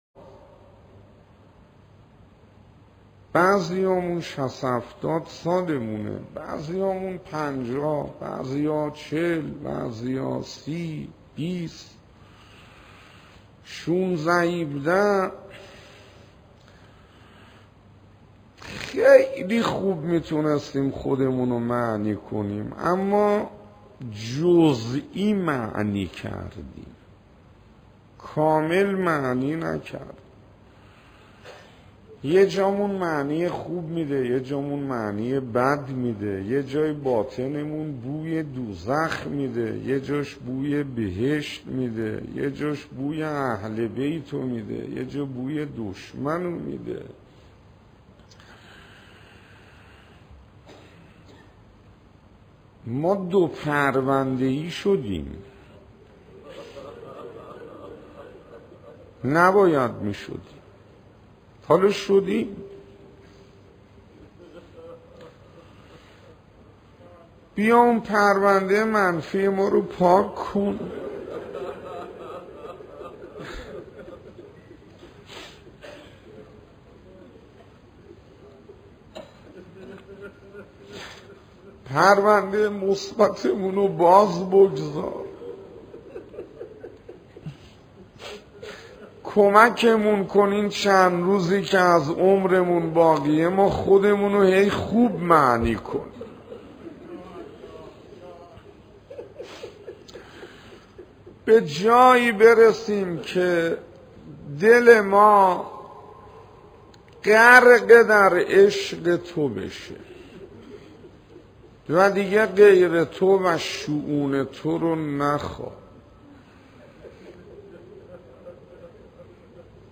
مناجات
پایگاه-اختصاصی-تولید-کلیپ-درس-اخلاق-بزرگان-و-روضه.mp3